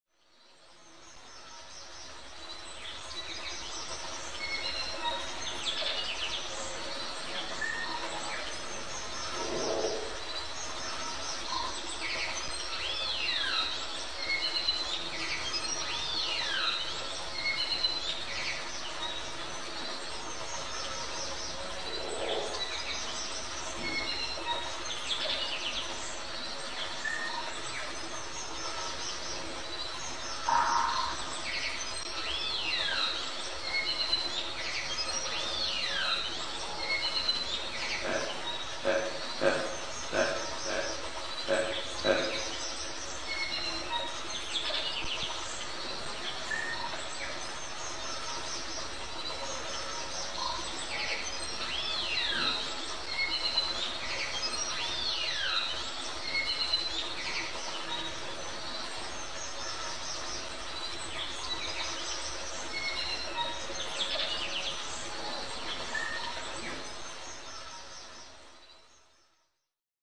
Frog Pond
Category: Animals/Nature   Right: Personal